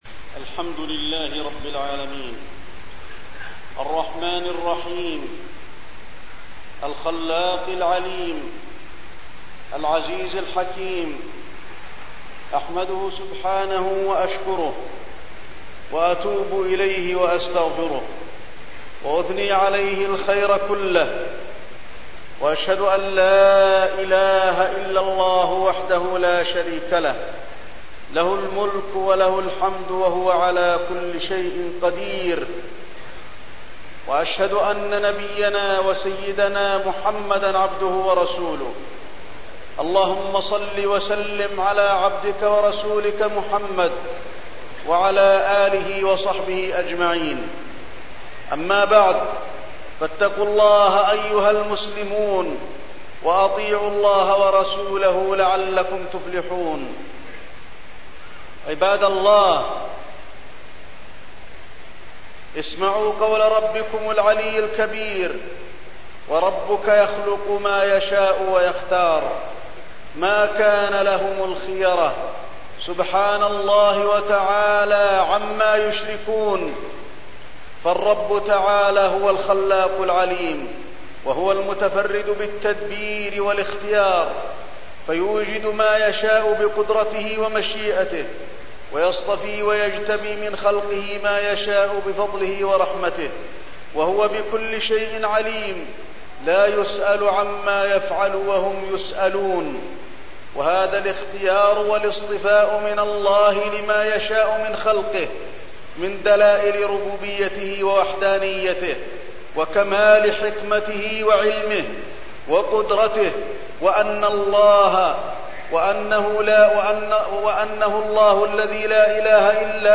تاريخ النشر ٢ ذو الحجة ١٤١١ هـ المكان: المسجد النبوي الشيخ: فضيلة الشيخ د. علي بن عبدالرحمن الحذيفي فضيلة الشيخ د. علي بن عبدالرحمن الحذيفي فضل عشر ذي الحجة